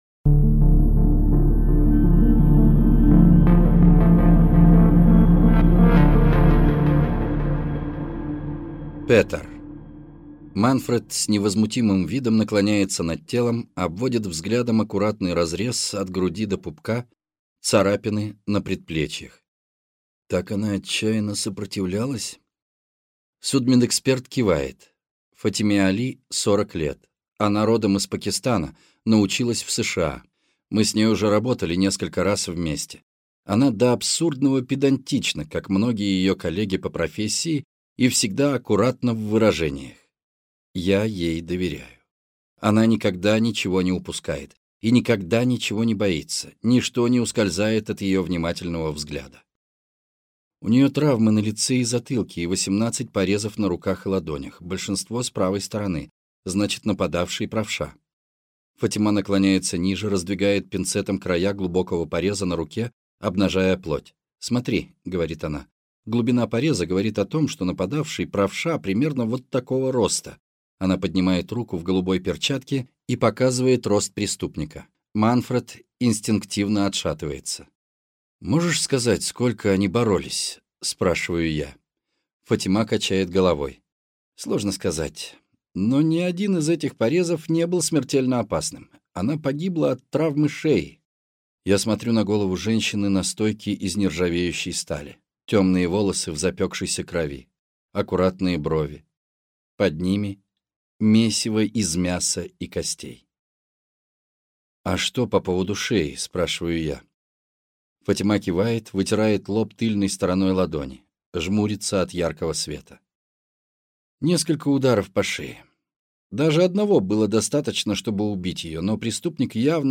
Аудиокнига На льду | Библиотека аудиокниг